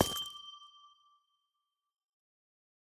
Minecraft Version Minecraft Version 1.21.5 Latest Release | Latest Snapshot 1.21.5 / assets / minecraft / sounds / block / amethyst / step12.ogg Compare With Compare With Latest Release | Latest Snapshot